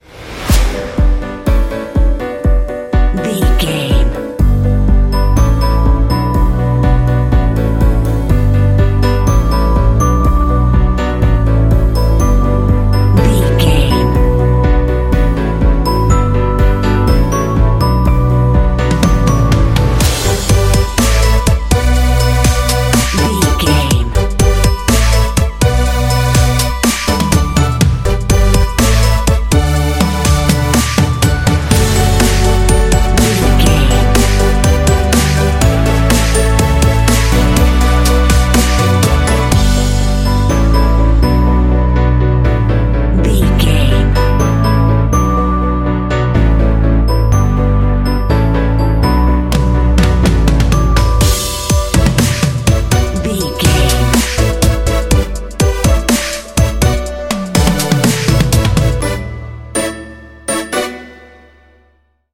This track makes for a groovy ambient underscore.
Uplifting
Aeolian/Minor
bright
joyful
piano
electric piano
drums
synthesiser
techno